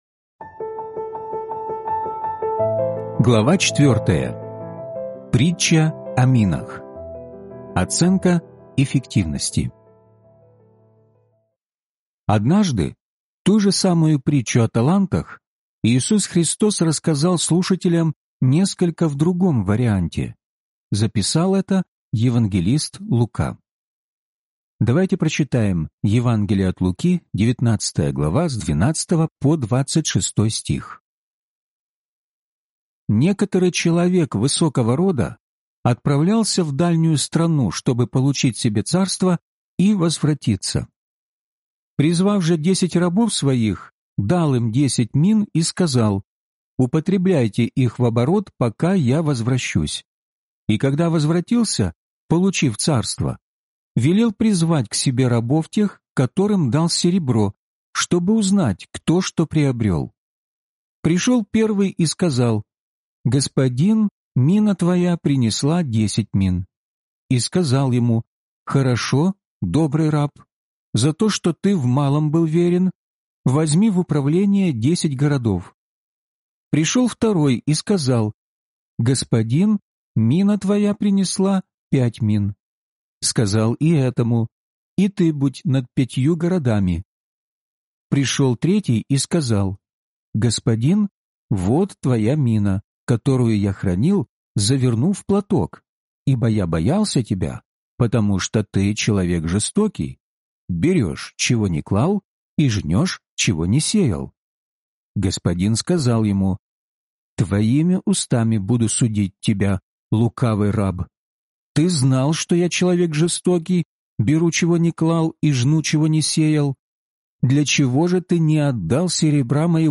Судилище Христово (аудиокнига) - День 4 из 12